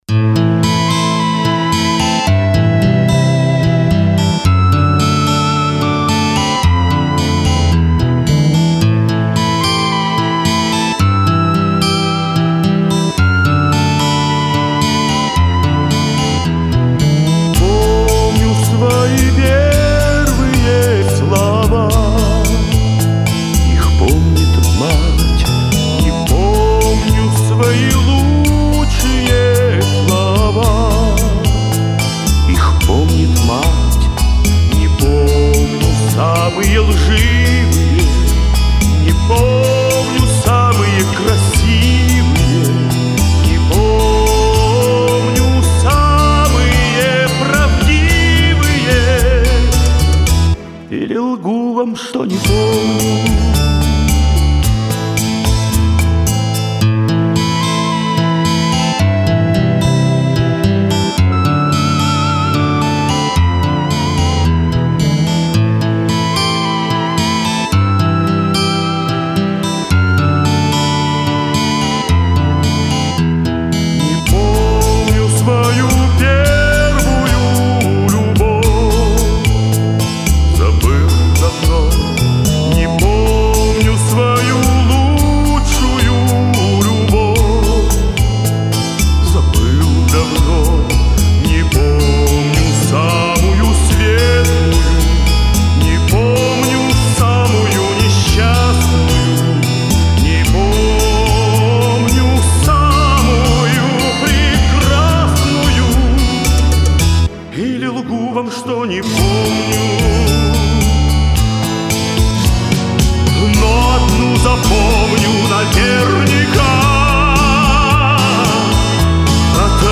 Все партии инструментов записаны им с помощью компьютера.